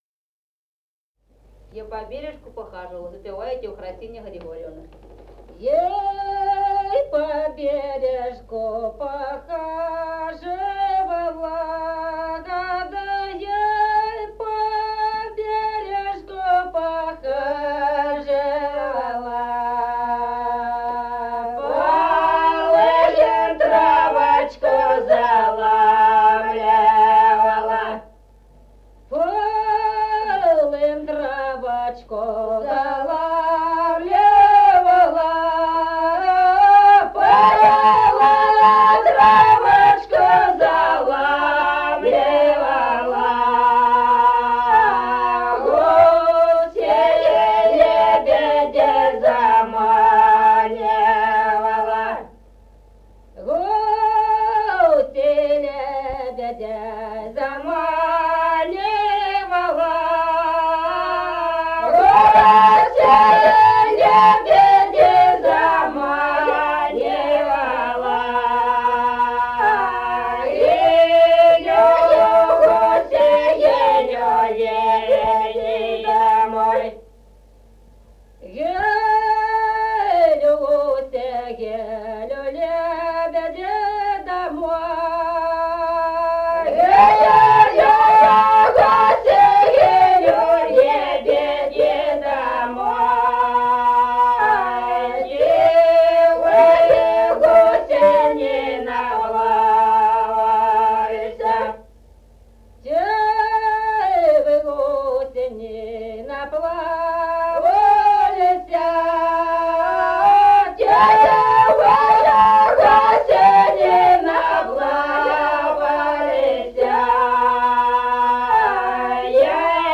Песни села Остроглядово. Я по бережку похаживала.